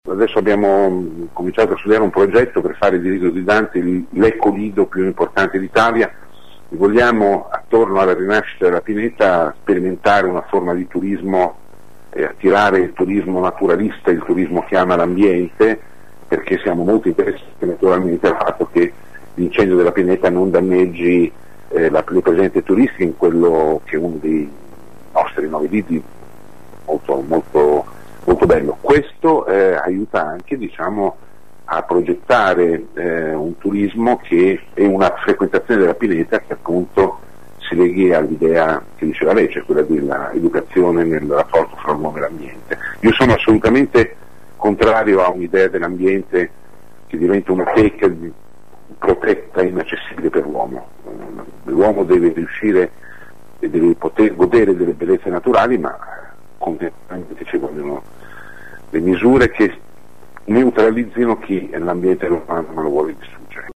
La pineta del Lido di Dante, nel Comune di Ravenna, è stata una delle aree di bosco colpite quest’anno dalle fiamme. Per migliorare il senso civico di chi frequenta e l’educazione ambientale dei cittadini il sindaco di Ravenna Fabrizio Matteucci ci anticipa che l’amministrazione vuole avviare un progetto per trasformare la pineta in un eco-lido per il turismo ambientale.